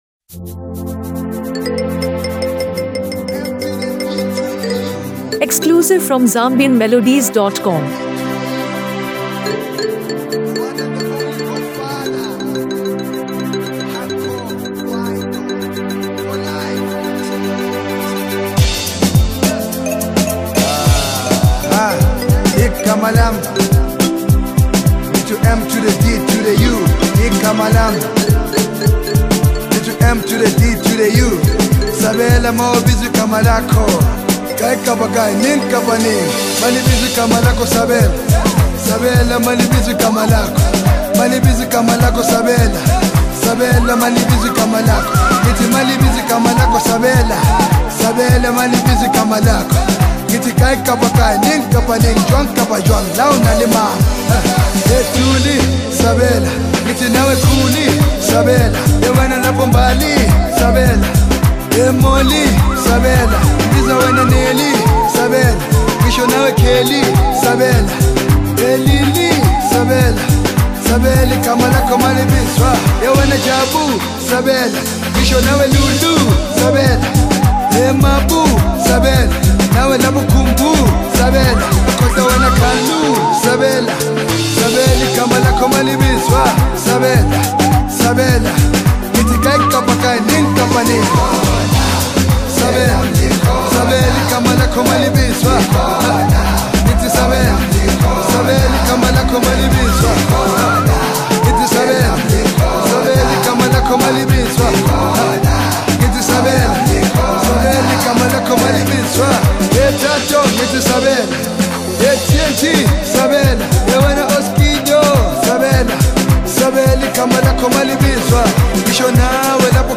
uplifting sounds